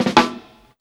130JAMROLL-L.wav